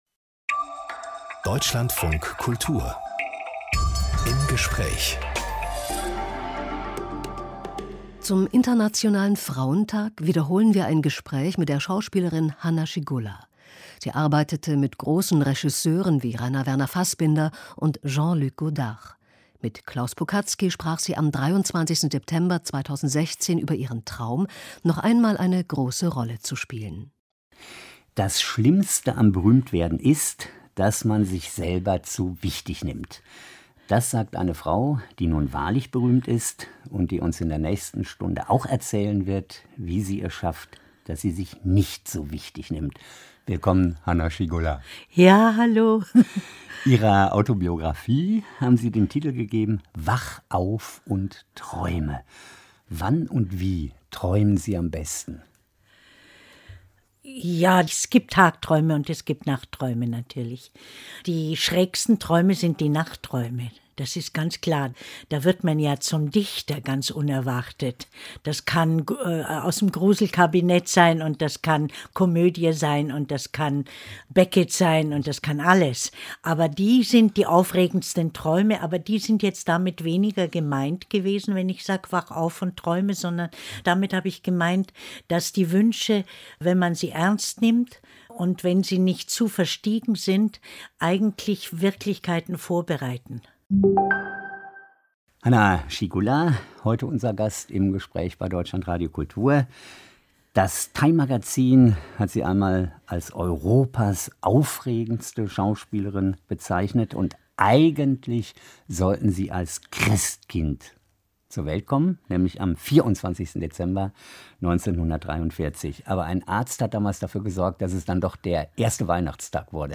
Zum Internationalen Frauentag wiederholen wir ein Gespräch mit der Schauspielerin Hanna Schygulla. Sie arbeitete mit Regisseuren wie Fassbinder und Godard.